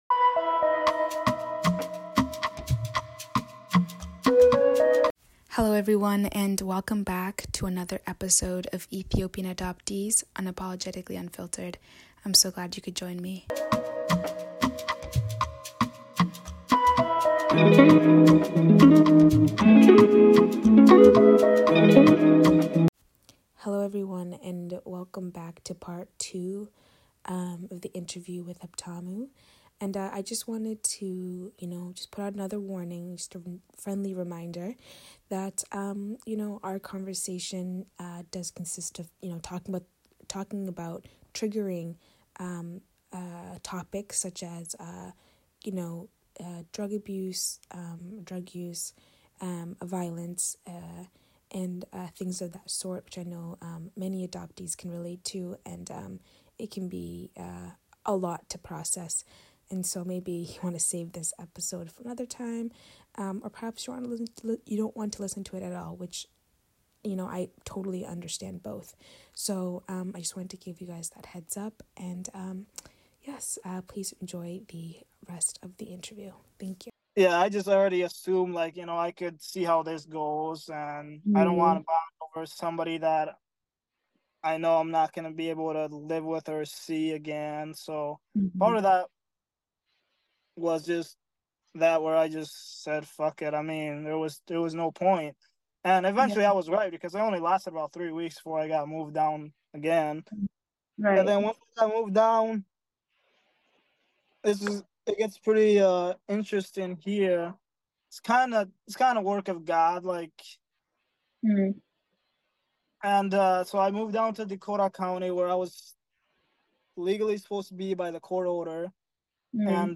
(Interview Part 2)